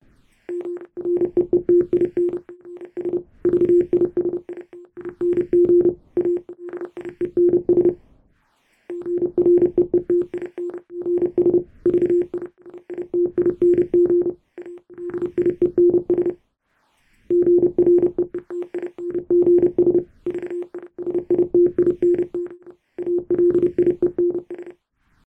morse.mp3